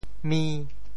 潮州发音 潮州 mi5 文